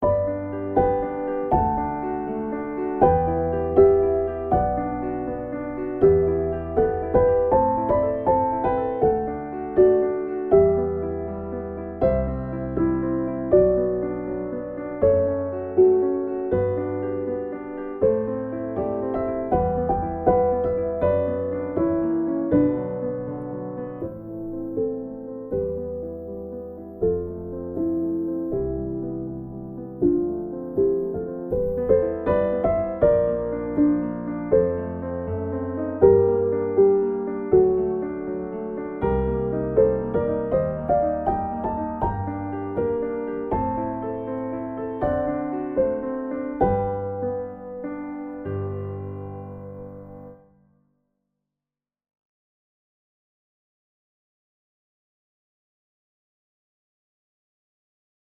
Op.26 No.1 deel 2 Berceuse Piano 3-handig december 2000